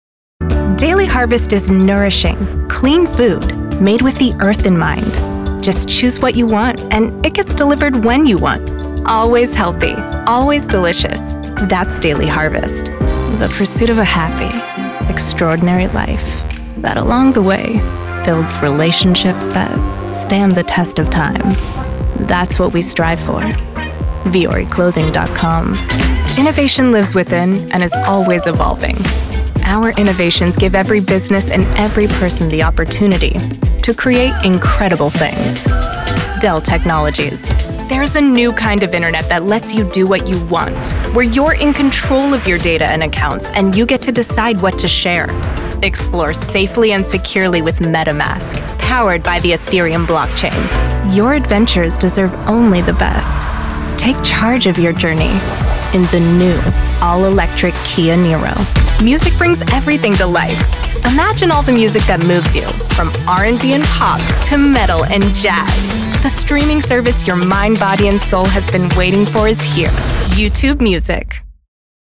Commercial Showreel
Female
American Standard
Bright
Friendly
Warm